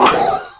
c_cerberus_atk3.wav